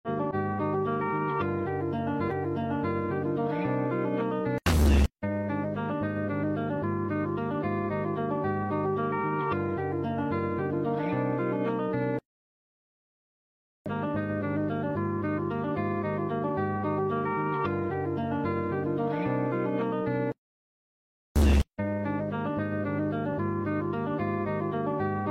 my horror 9999 glitch 1999 sound effects free download